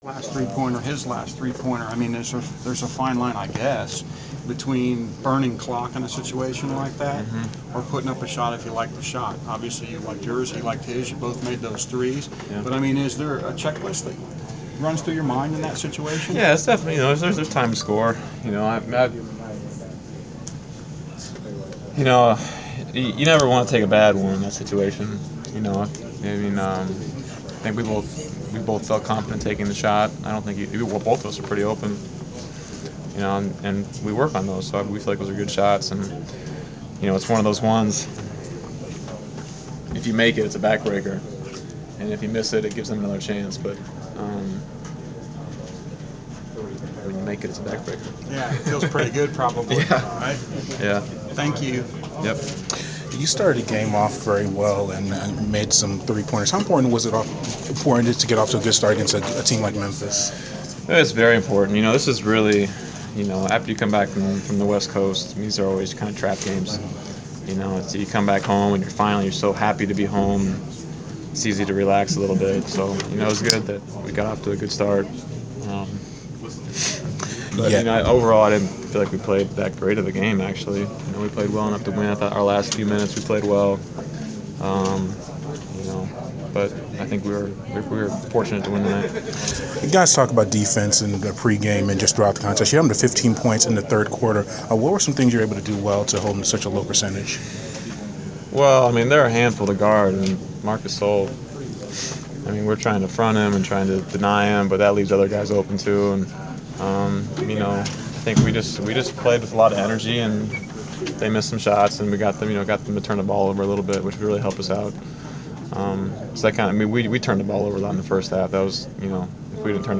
Inside the Inquirer: Postgame interview with Atlanta Hawks’ Kyle Korver (1/7/15)